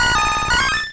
Cri d'Empiflor dans Pokémon Rouge et Bleu.